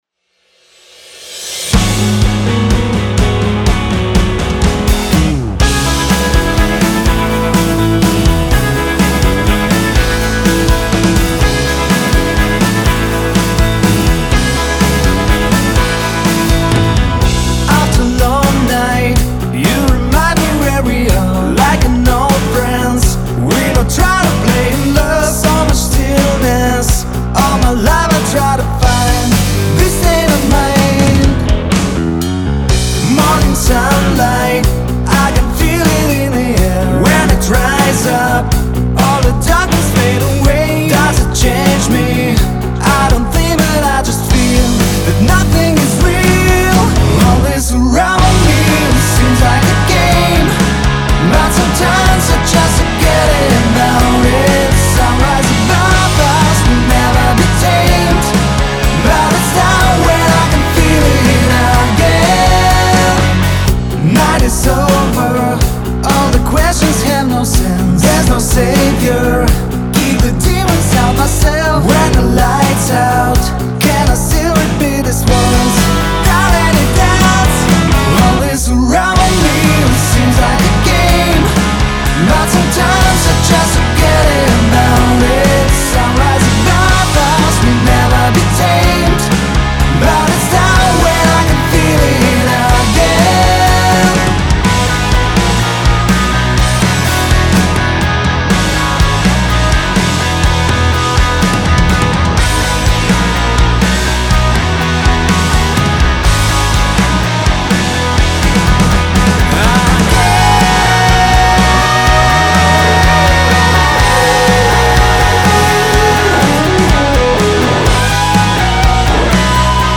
Все гитары DI через кабсимы на фото.